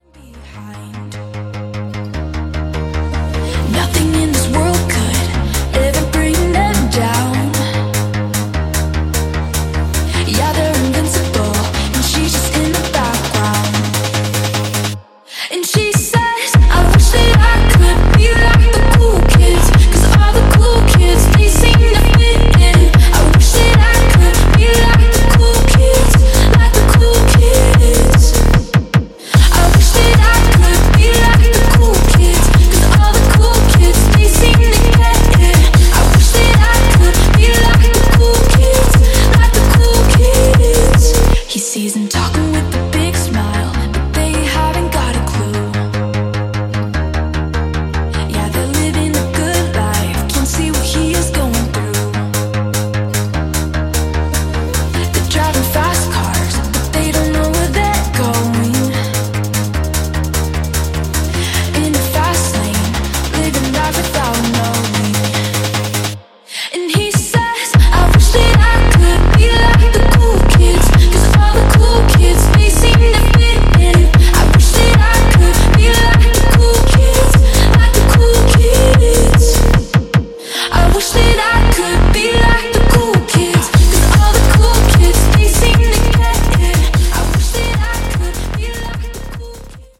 Genres: 90's , DANCE , RE-DRUM
Clean BPM: 136 Time